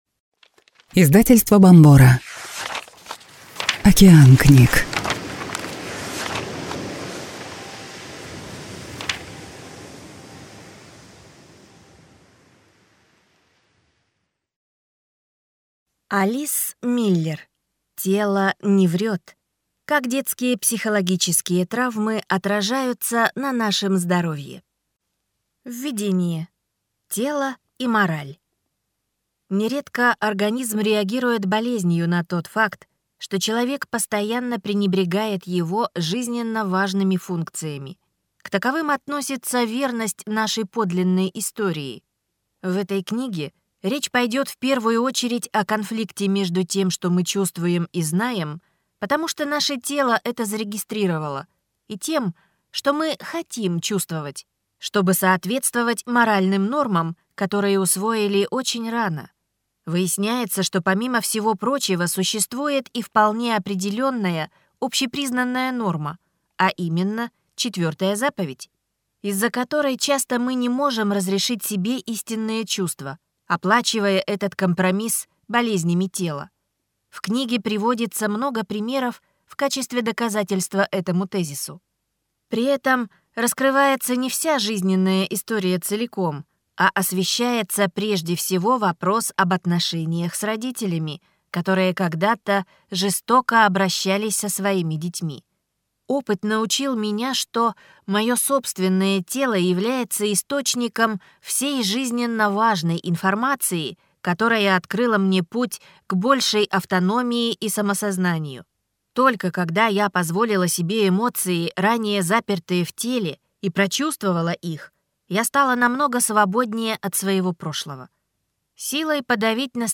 Аудиокнига Тело не врет.